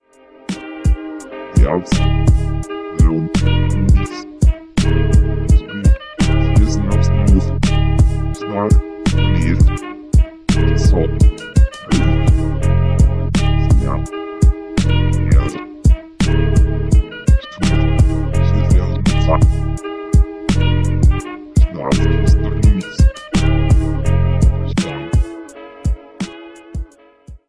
Sie sprechen nur Kauderwelsch.
Die Koordinaten klingen ja ganz rhythmisch.